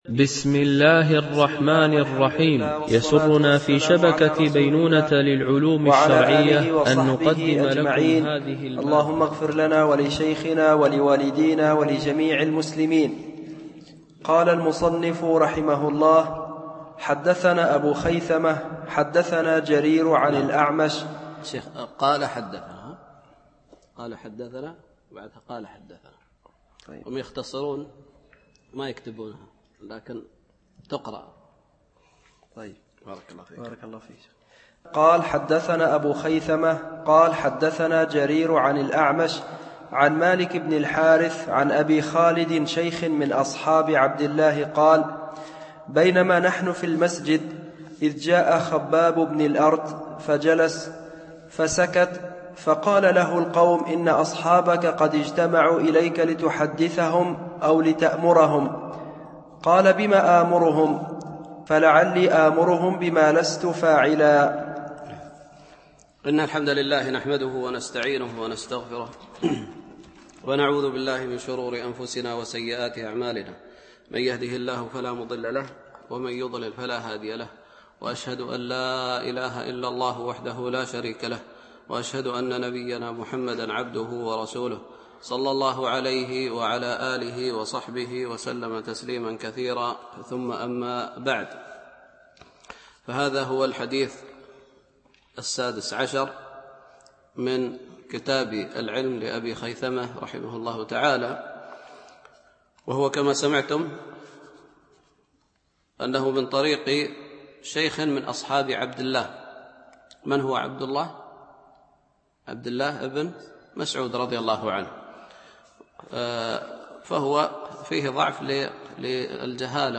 شرح كتاب العلم لأبي خيثمة ـ الدرس 6 (الأثر 16- 17)